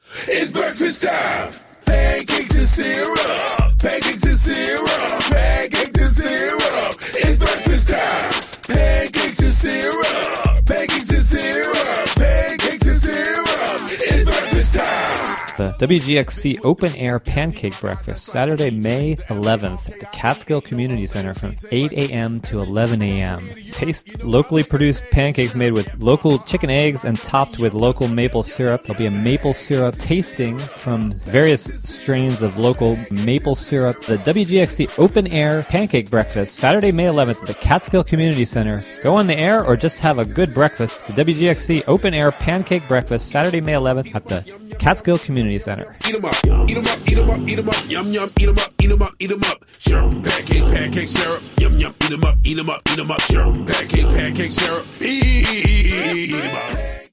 An Official PSA for WGXC Open Air Pancake Breakfast May 11 at Catskill Community Center. (Audio)